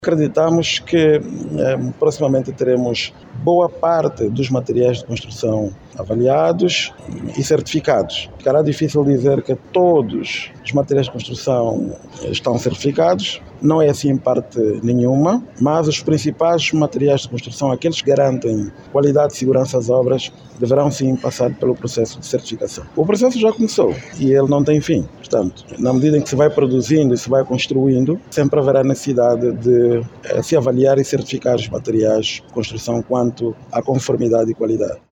O director-geral do INIQ, Carmo dos Santos, afirma que o processo poderá ser concluído em breve, especialmente no que diz respeito aos materiais que garantem a qualidade e segurança das obras de construção civil.